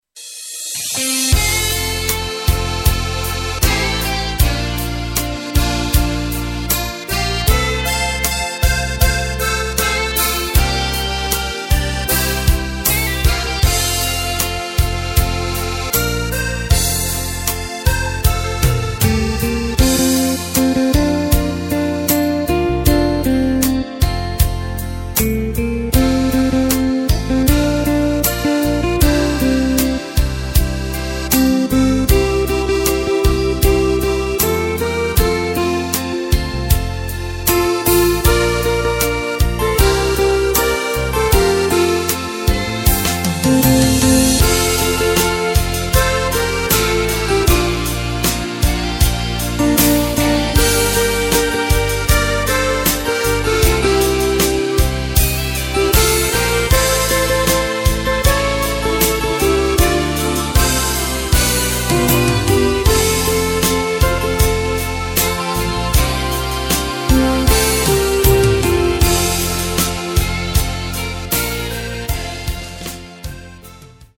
Takt:          4/4
Tempo:         78.00
Tonart:            F
Schlager aus dem Jahr 2012!